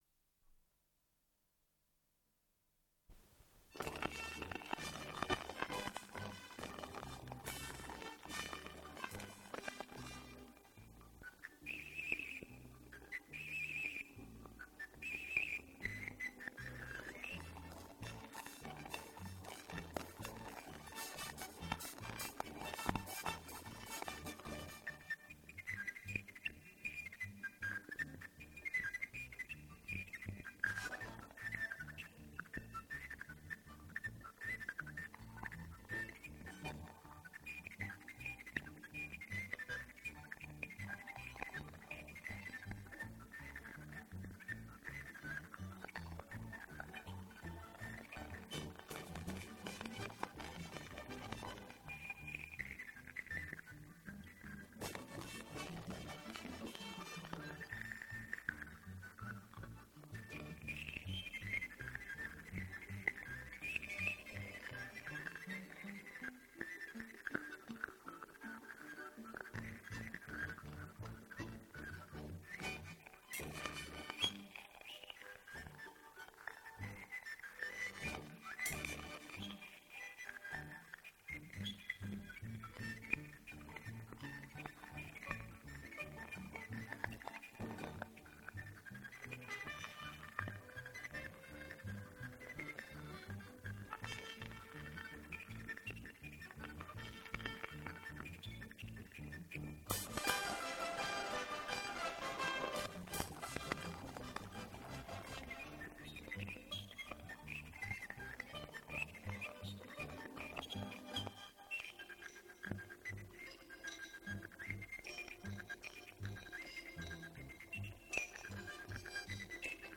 4) " Полянка"  Русская народная  Автор обработки  Марк Вахутинский.
Свирель) , Оркестр русских народных инструментов " Русские узоры".
Запись 1980 год Дубль моно
russkaya-narodnaya--svirel----polyanka.mp3